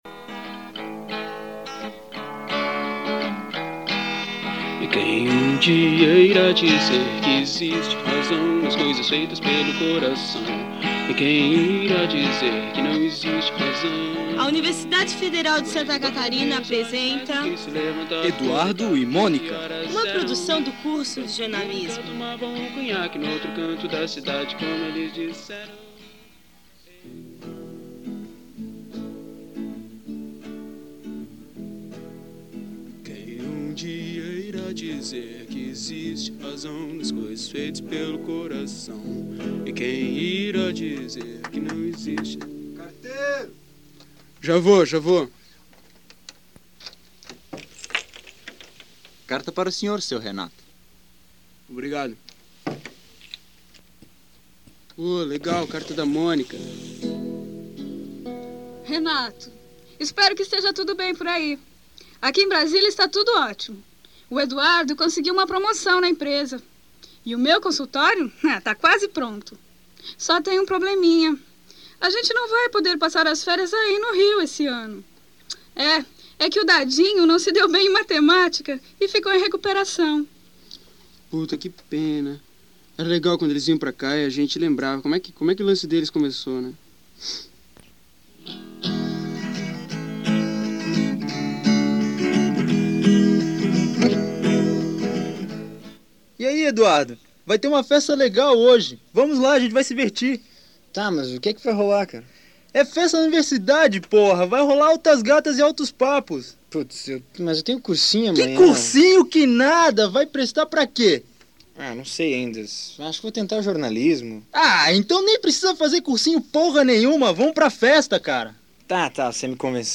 Abstract: Uma versão para radioteatro da famosa música da banda Legião Urbana.